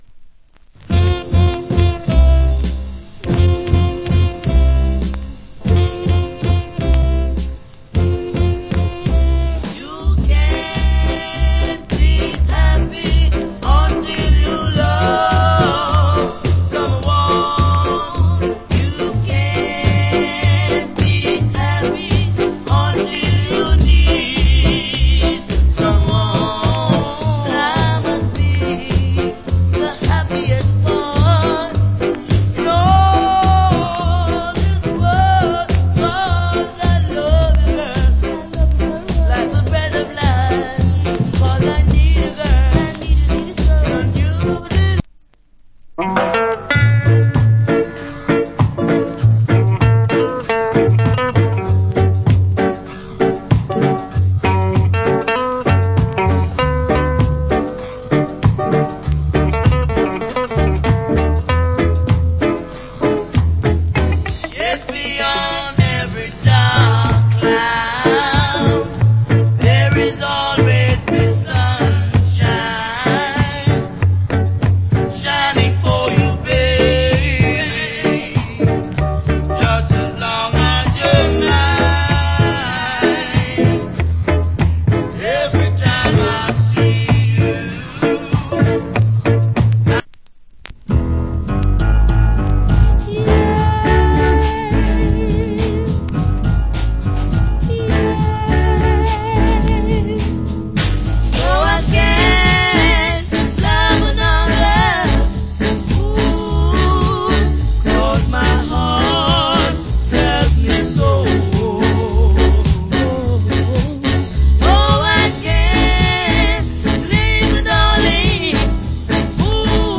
Great Rock Steady Vocal. 90's JA Press.